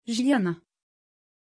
Aussprache von Jiliana
pronunciation-jiliana-tr.mp3